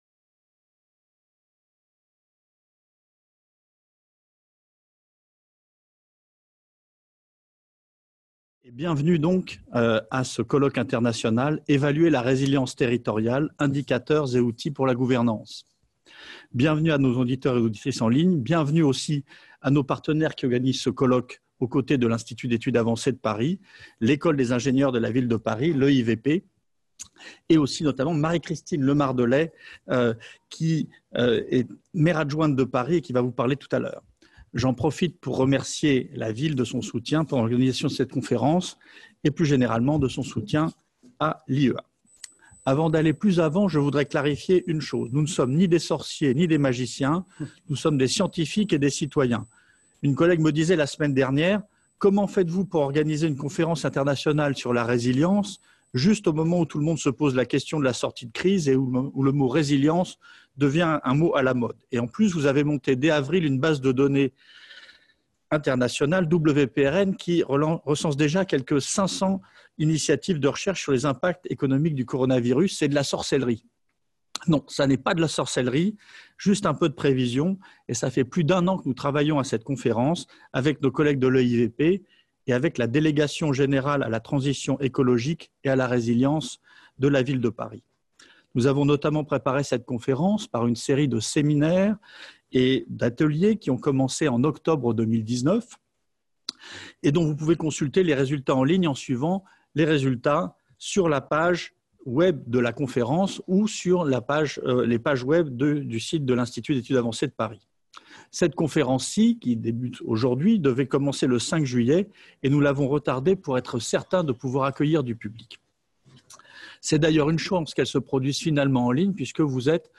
ouverture officielle du colloque | Canal U